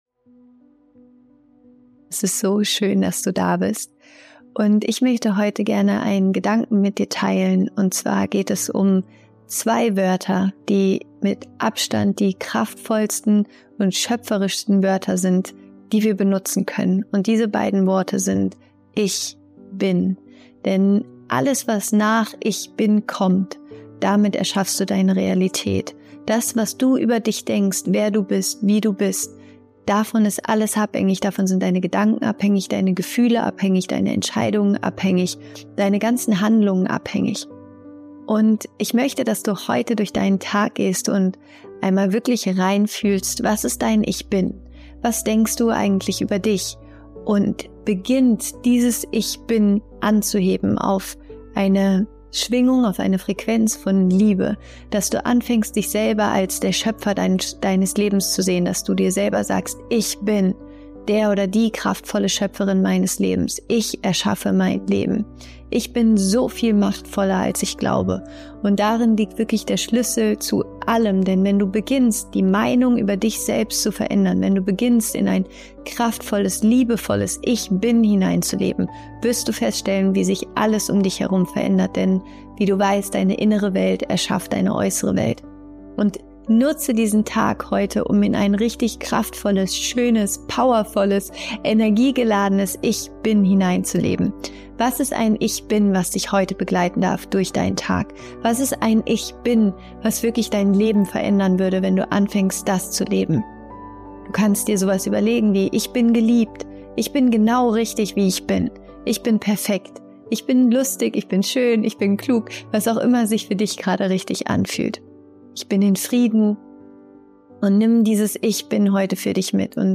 Guten Morgen! Heute habe ich eine kurze, liebevolle Voice Message für dich – ein persönlicher kleiner Reminder, der dich daran erinnert, wie wertvoll du bist. 🌟